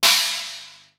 • Cymbal B Key 02.wav
Royality free cymbal tuned to the B note. Loudest frequency: 4549Hz
cymbal-b-key-02-vZF.wav